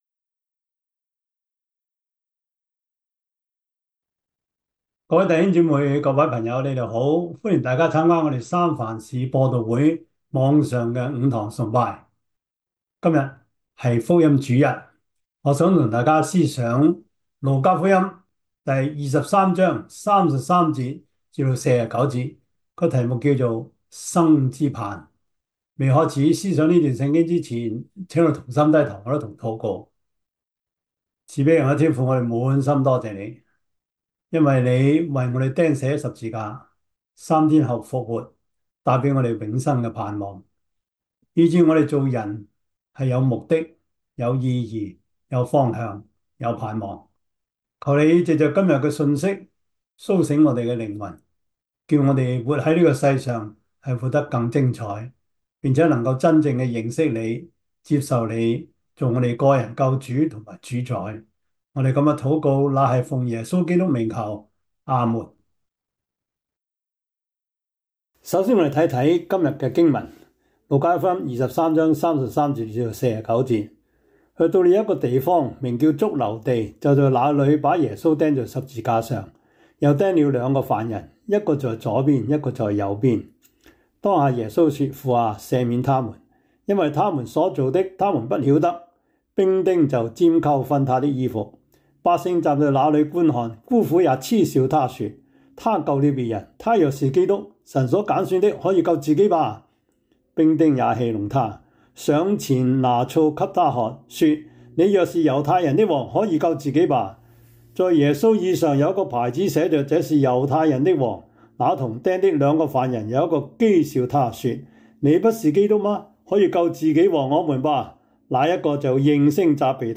Service Type: 主日崇拜
Topics: 主日證道 « 還有疑惑嗎?